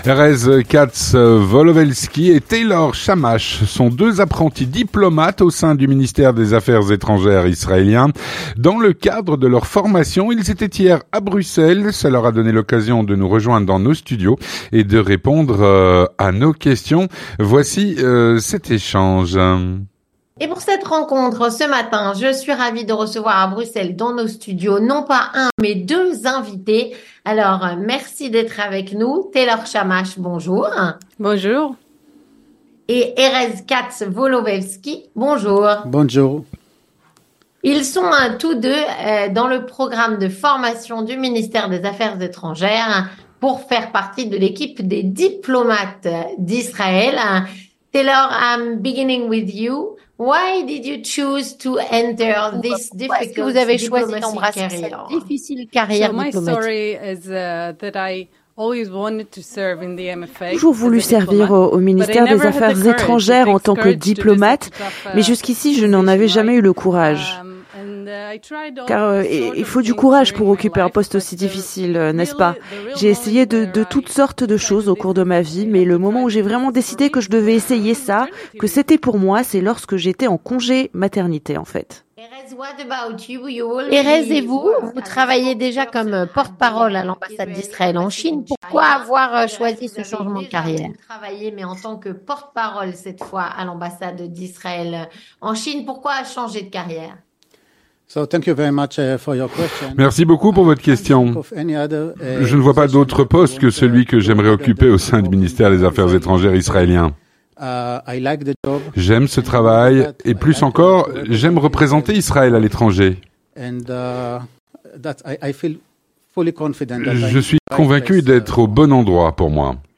Rencontre - 2 "apprentis diplomates" israéliens sont venus dans nos studios.
2 "apprentis diplomates" au sein du Ministère des Affaires étrangères israélien étaient hier à Bruxelles. On les a reçus dans nos studios.